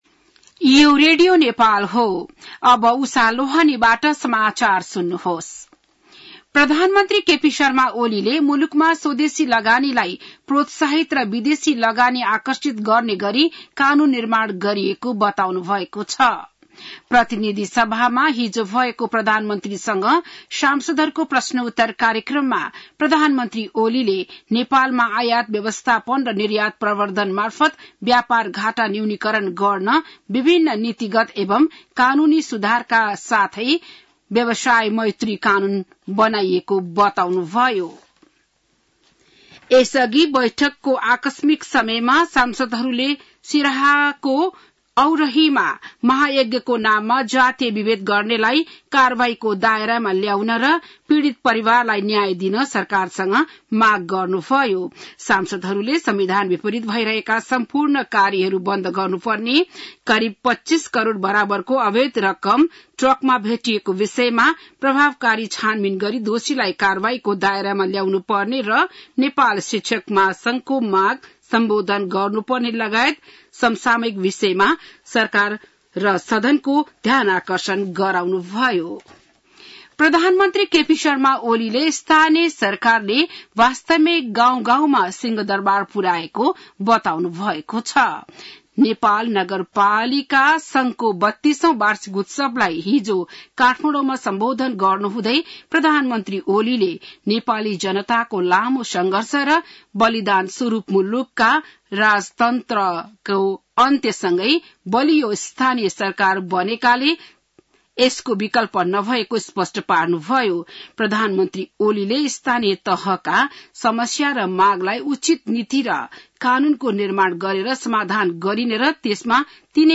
An online outlet of Nepal's national radio broadcaster
बिहान १० बजेको नेपाली समाचार : ८ चैत , २०८१